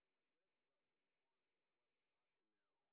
sp25_train_snr0.wav